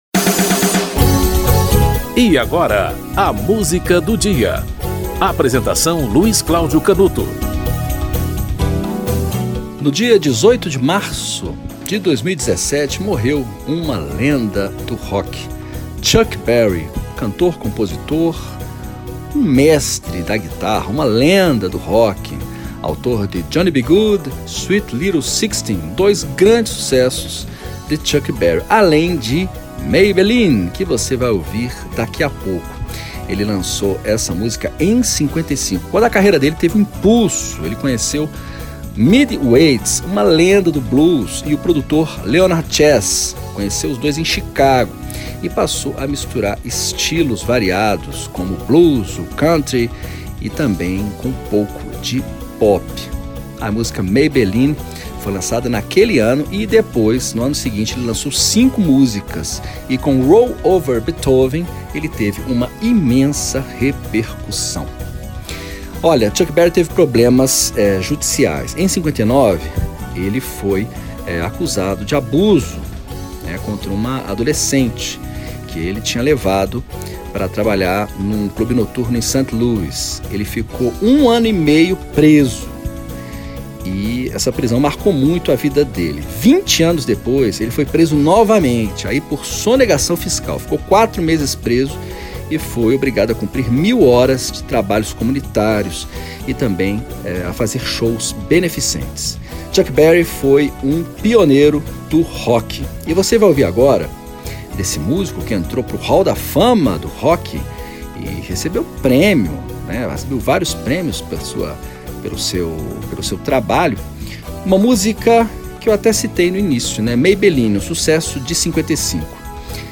Igor Markevitch e Orquestra da Rádio e Televisão Espanhola - Parade (Erik Satie)